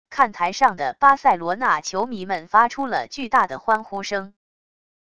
看台上的巴塞罗那球迷们发出了巨大的欢呼声wav音频